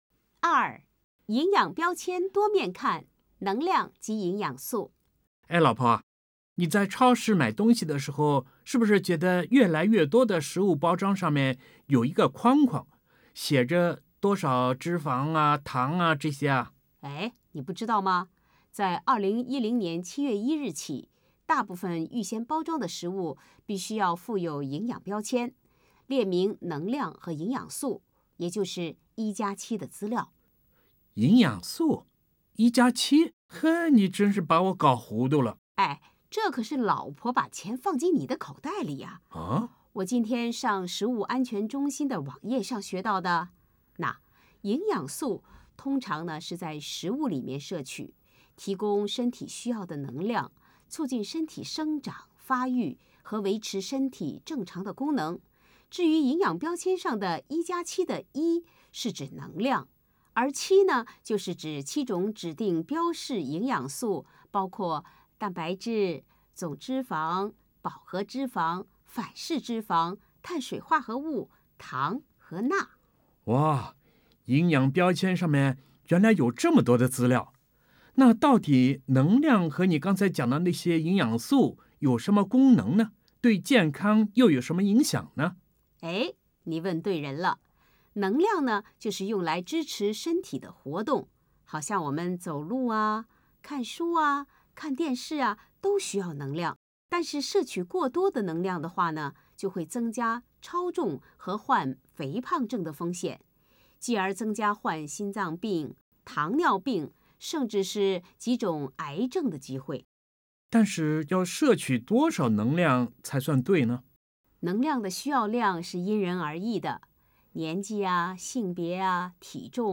VIII. 录音短剧